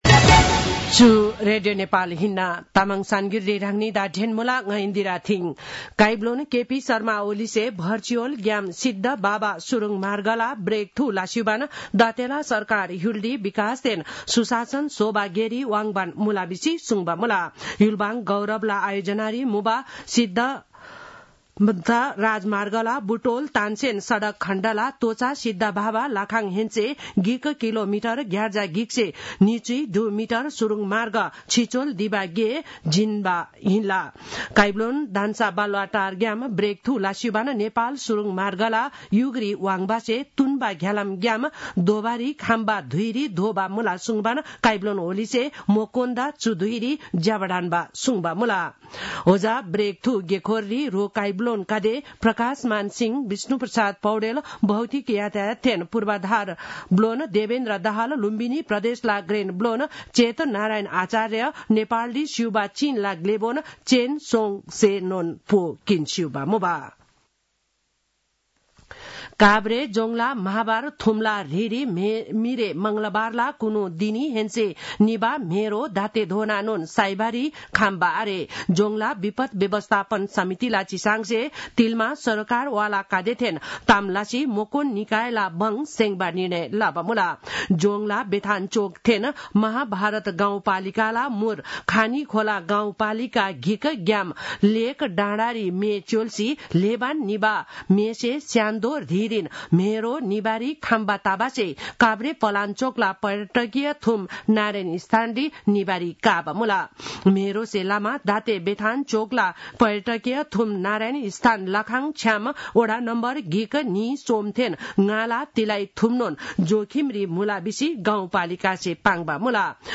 तामाङ भाषाको समाचार : १२ माघ , २०८१
Tamang-news-10-11.mp3